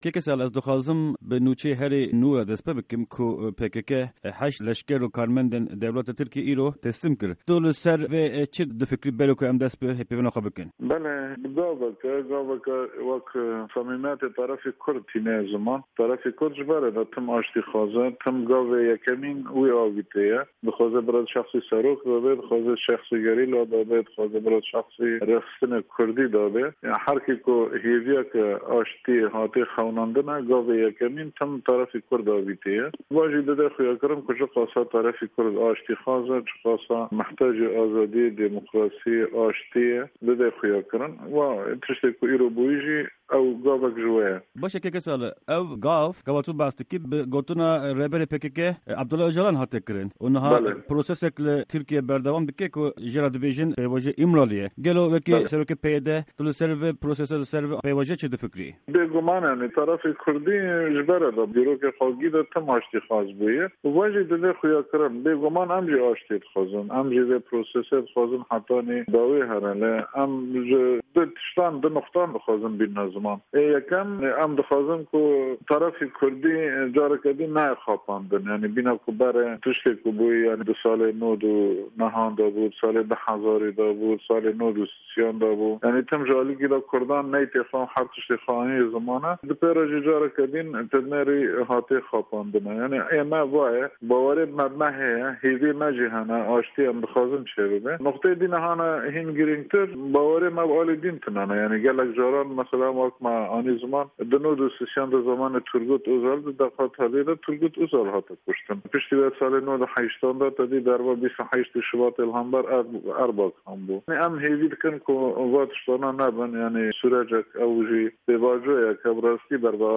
Di hevpeyvîna Pişka Kurdî ya Dengê Amerîka de, endamê Desteya Bilind a Kurd û Serokê Partîya Yekîtîya Demokrat (PYD) Salih Mihemed Mislim li ser azadkirina 8 kesên girtî dike û vê biryarê gaveke pir erênî dinirxîne.
Hevpeyvîn_Salih_Mislim